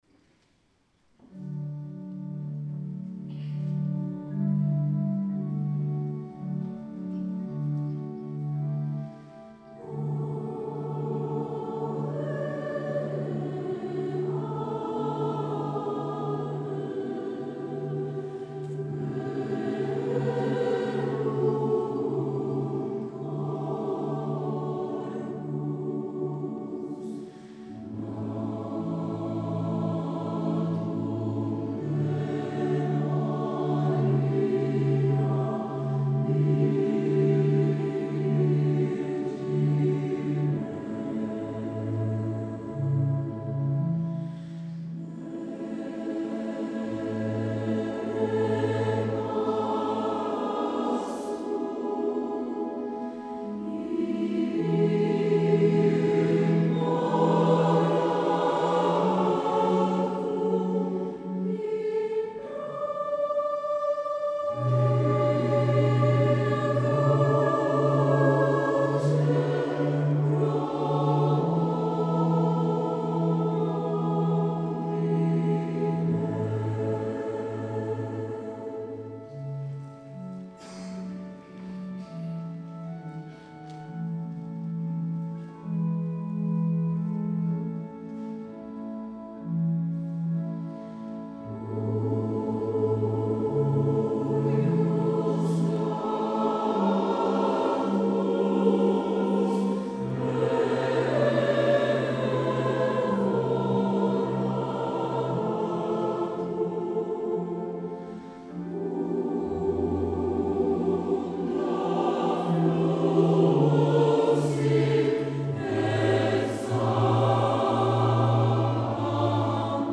La Corale San Gaudenzio di Gambolo' (Church Choir)2015
di alcuni brani del concerto.
a Cori uniti